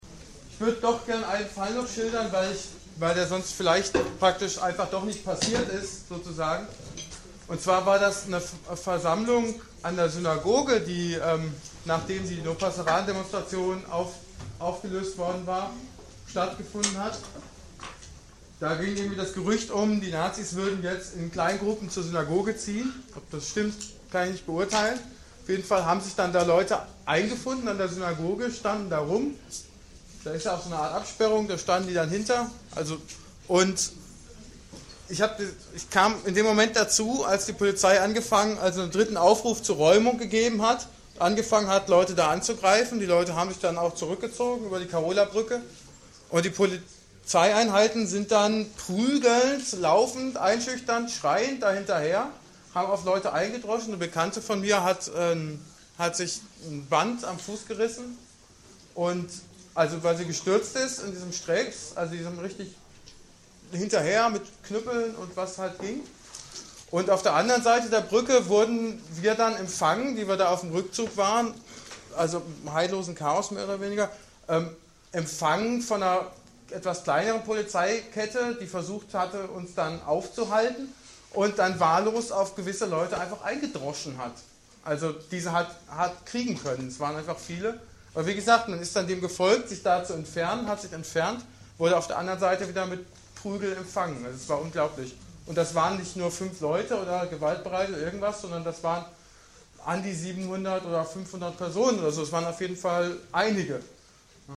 Einschätzungen und Berichte zum Polizeieinsatz am 13./14.2. aus der Anhörung der Linksfraktion im Landtag am 3.3.2009 | AK Antifa Dresden
12. Augenzeuge